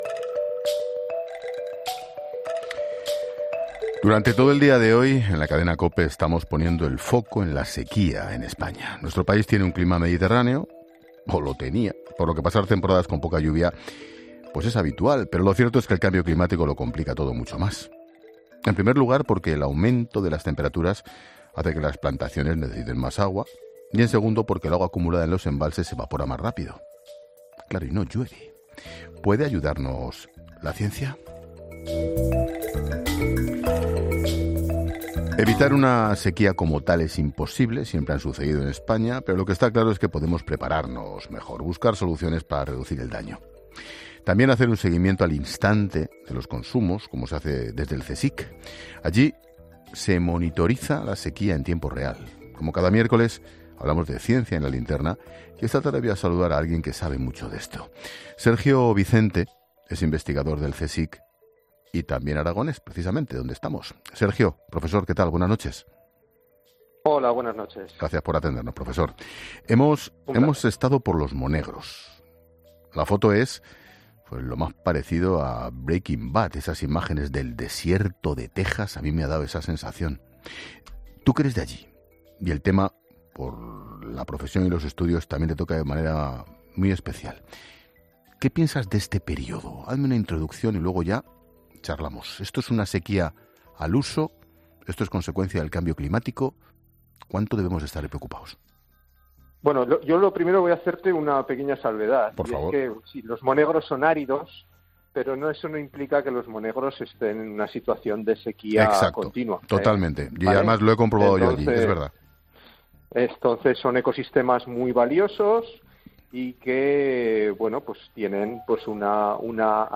El director de 'La Linterna', Ángel Expósito, habla desde Monegros